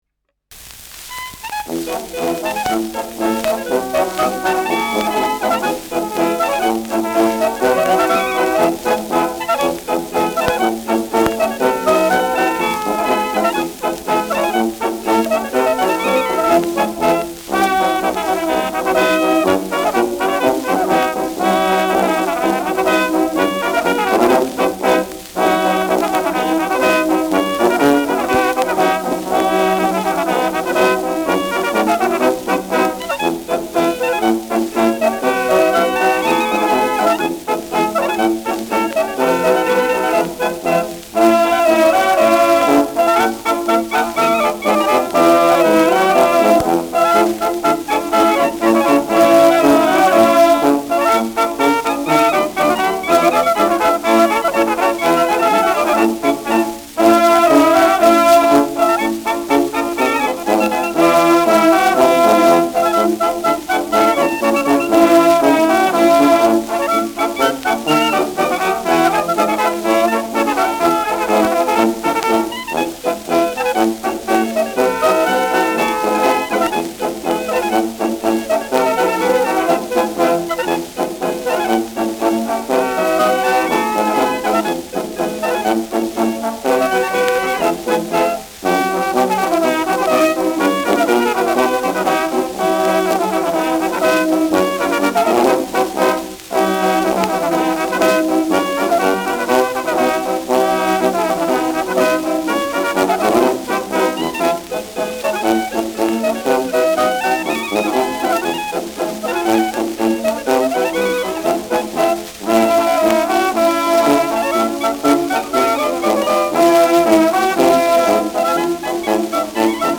Schellackplatte
präsentes Rauschen : abgespielt : leichtes Leiern : leichtes Knistern : gelegentliches Knacken